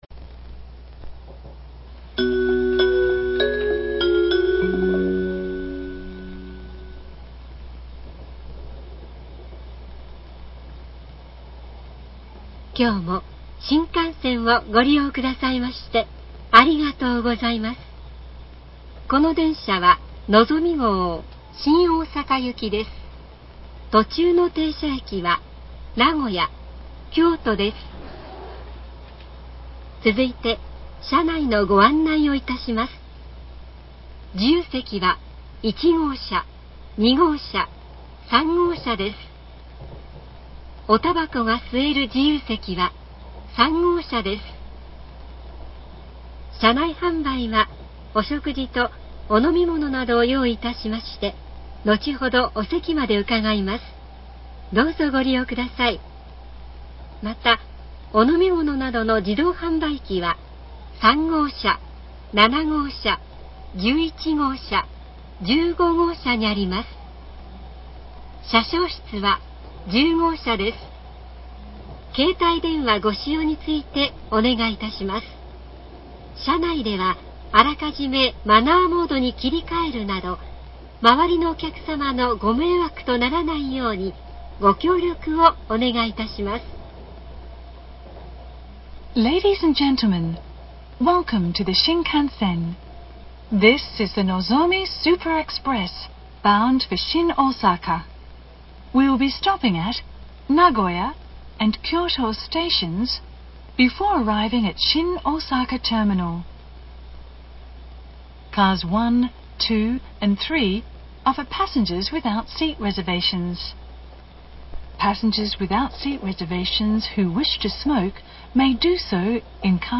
JR東海　車内放送
東京発車後
品川発車後名古屋到着前
名古屋発車後京都到着前新大阪到着前   アンビシャスジャパン